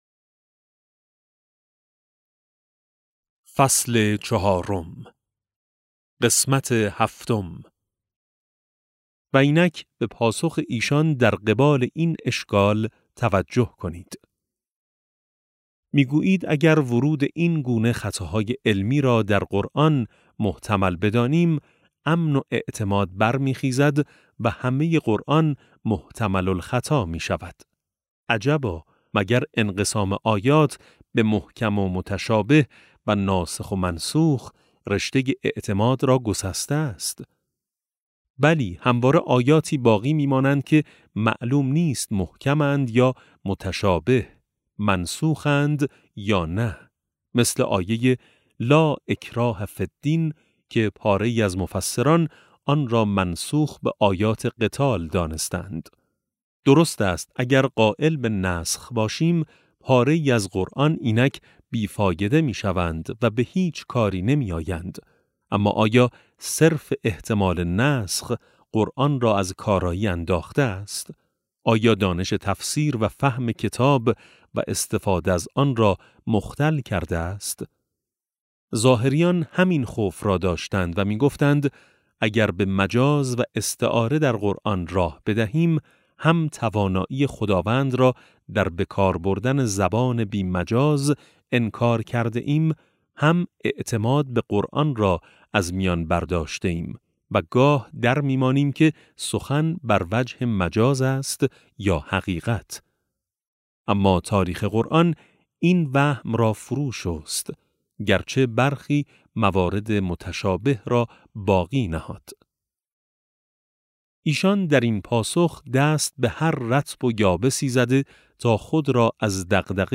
افق وحی - فصل چهارم (391 ـ 408) - کتاب صوتی - کتاب صوتی افق وحی - بخش22 - آیت‌ الله سید محمد محسن طهرانی | مکتب وحی